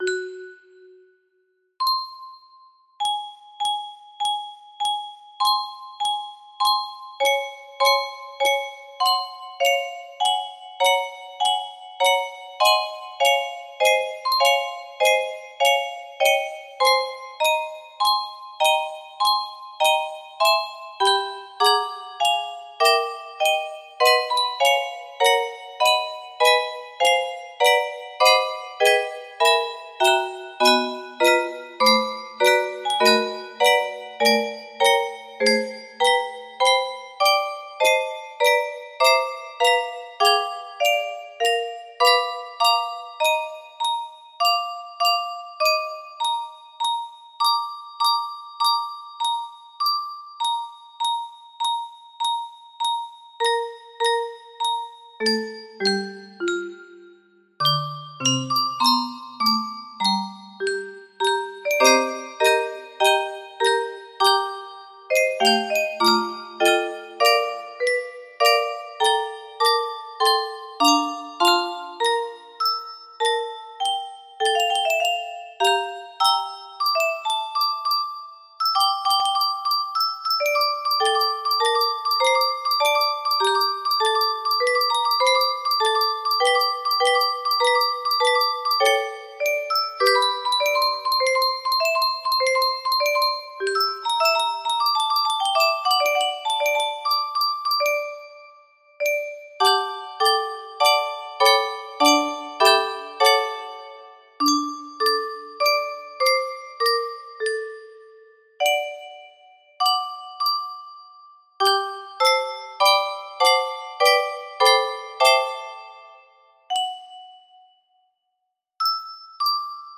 Track 4 music box melody